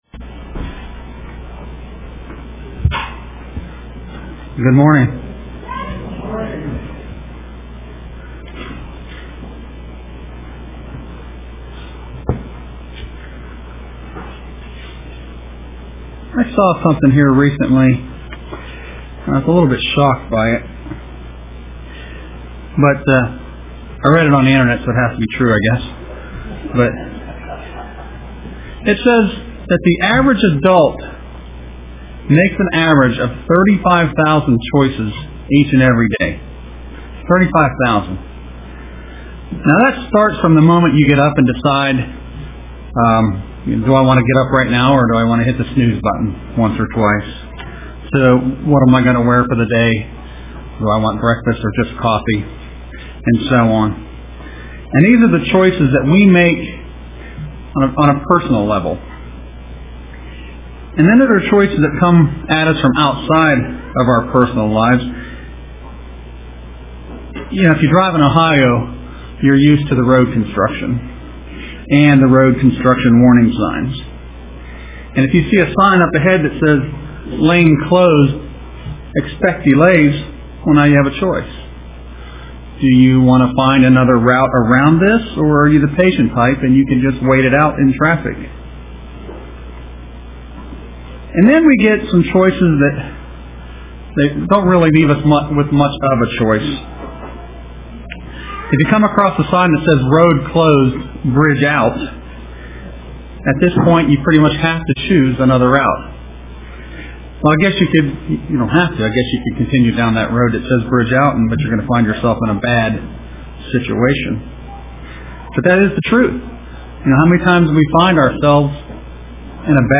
Print Conscious Choices UCG Sermon